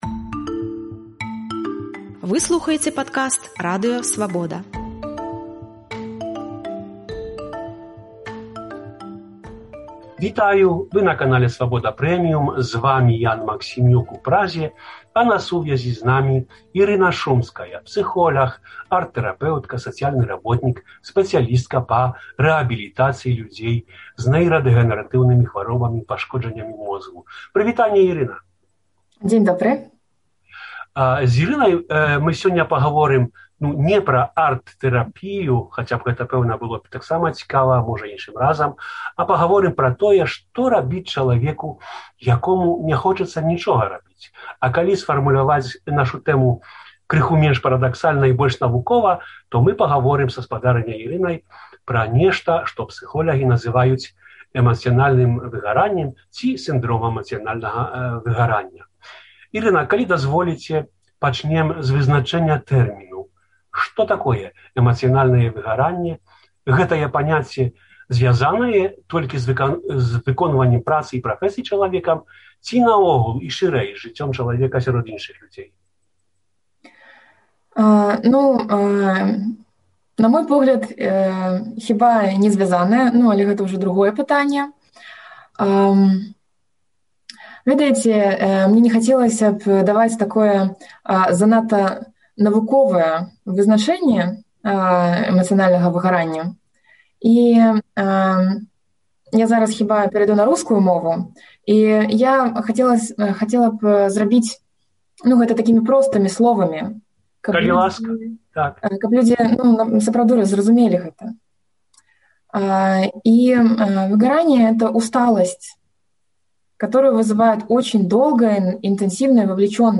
Што рабіць, калі нічога ня хочацца рабіць? Размова з псыхатэрапэўткай пра эмацыйнае выгараньне